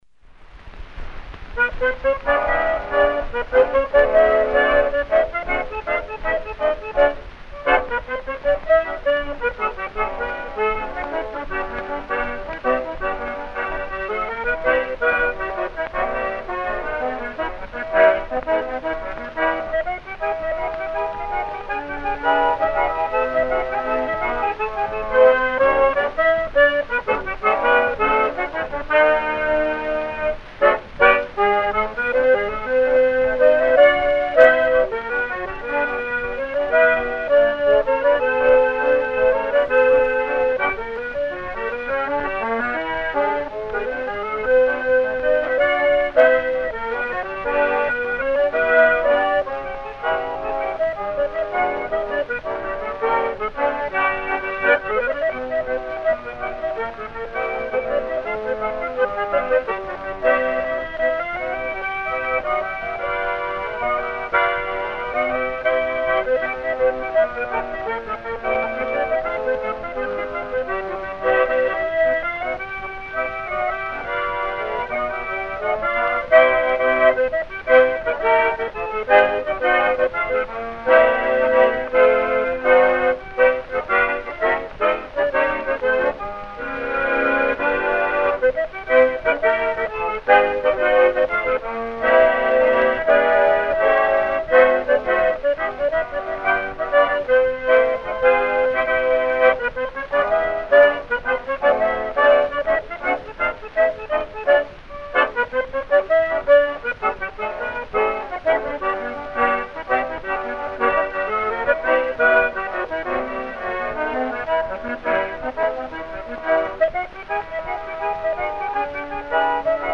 Accordion
(Vintage recording)